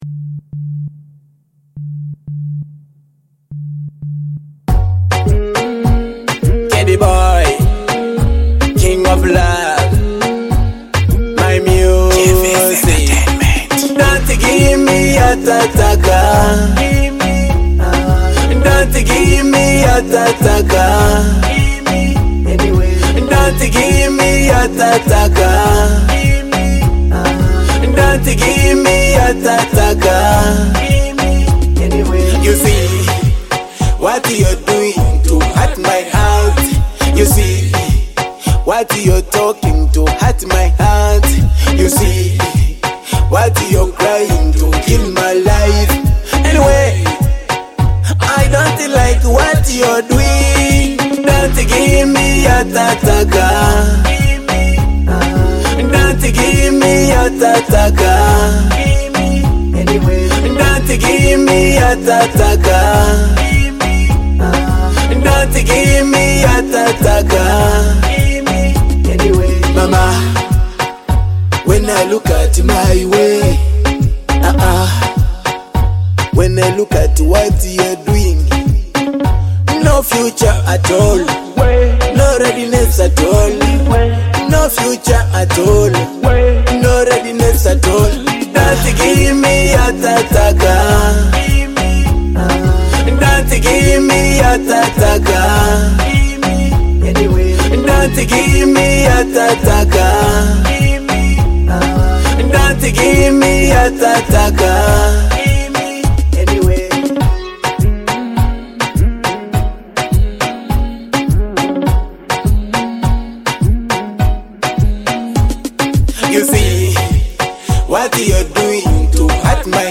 A Teso Sound with Global Vibes!